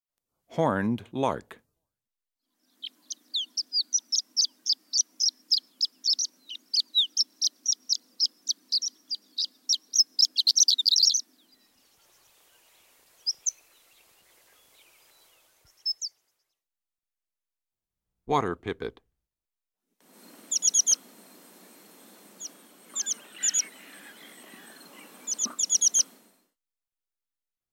Index of /songs/Animals/Birds/Bird Songs Eastern-Central
19 Horned Lark-American Pipit.mp3